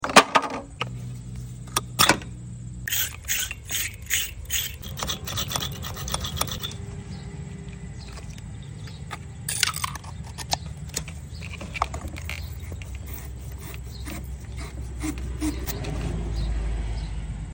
Battery terminal cleaning tool asmr sound effects free download